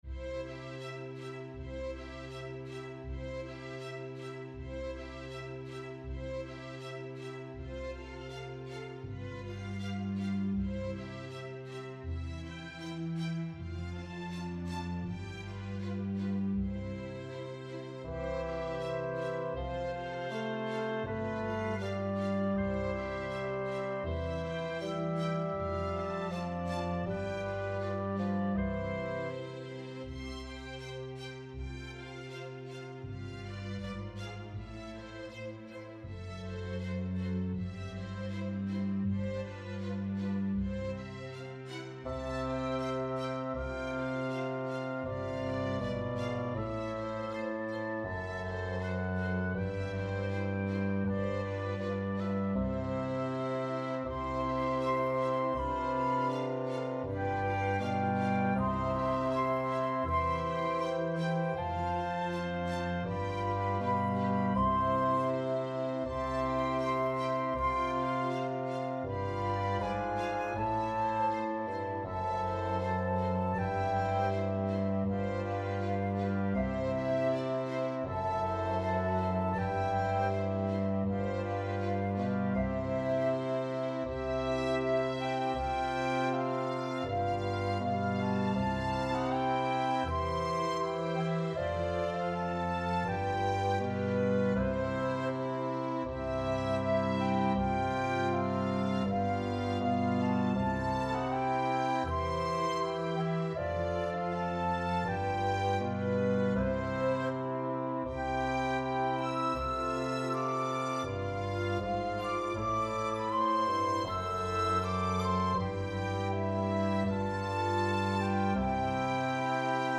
Underscore
Reduced arrangement